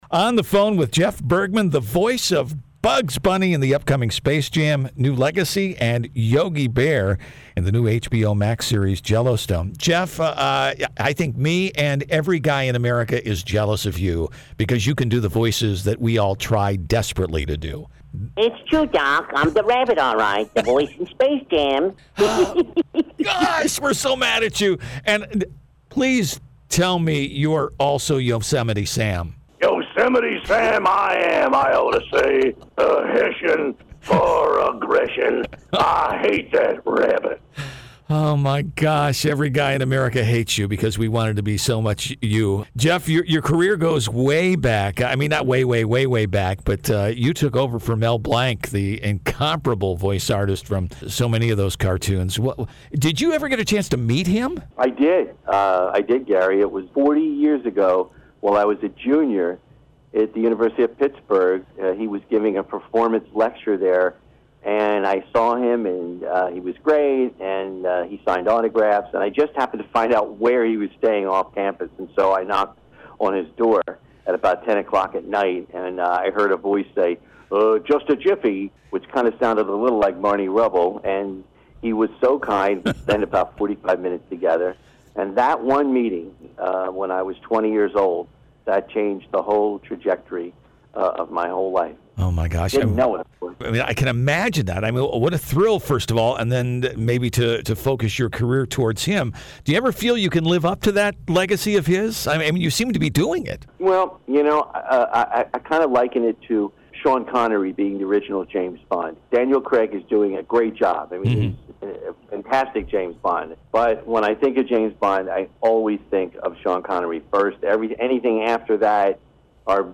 INTERVIEW WITH JEFF BERGMAN